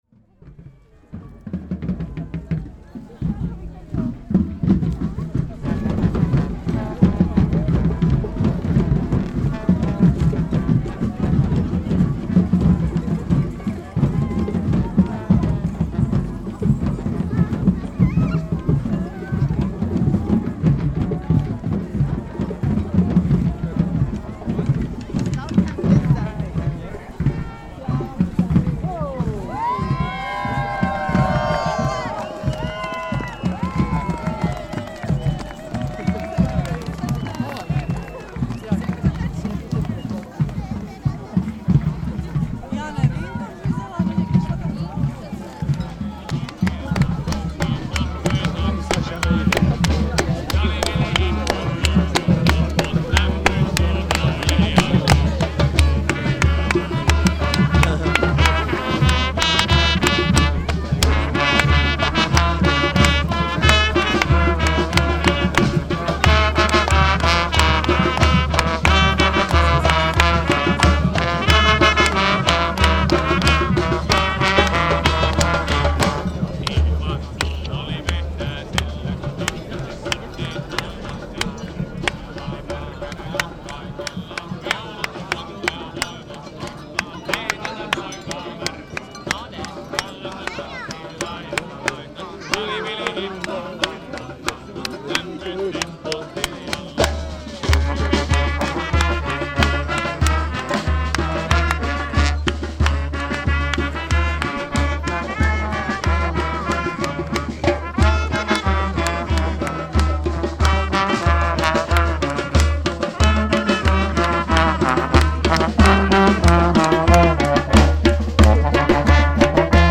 field recordings, sound art, radio, sound walks
Tagy: exteriér hudba lidé periferie
Šel jsem z Roztok a nahrával pochodující keltskou dechovku a bubenickou tlupu blabuburo. Na Holém vrchu se podařilo pořadatelům zformovat dva obrovské kruhy několika stolek lídí, kteří tančili v protisměru.